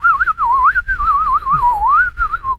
bird_sparrow_tweet_03.wav